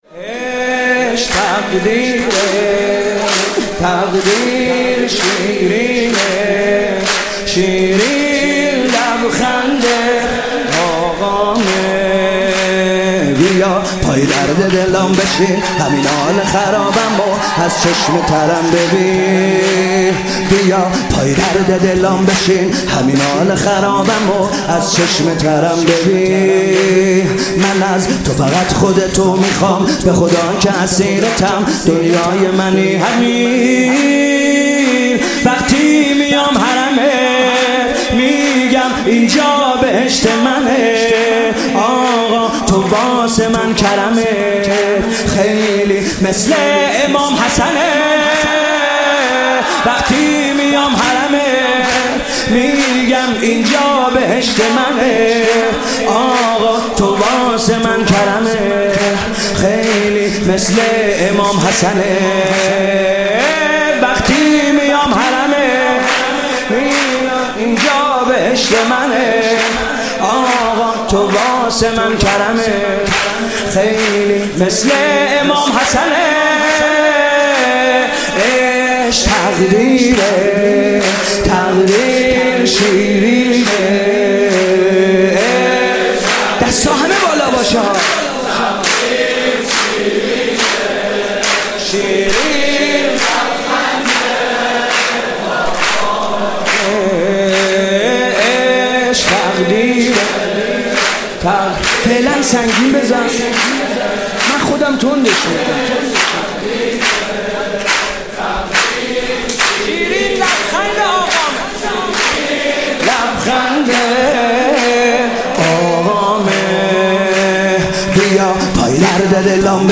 سرود جدید